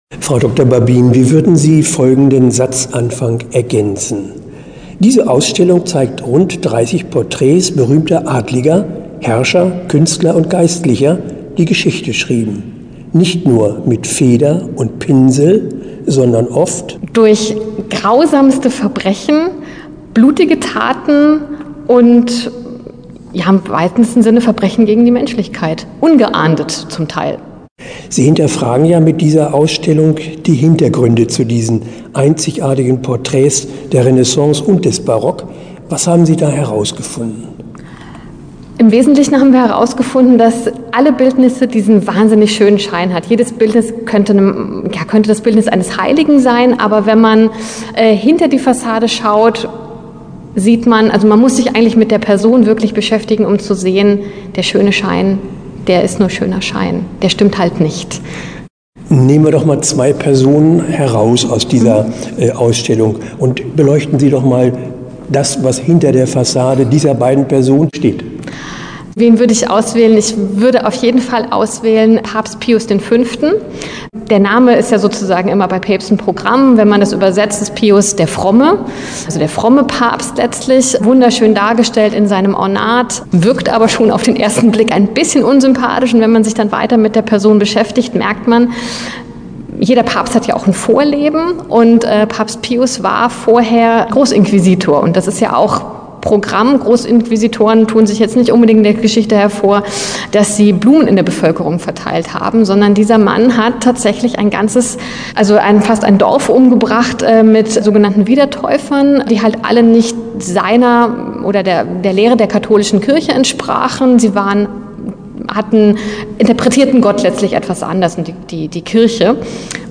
Interview-True-Crime-Cast.mp3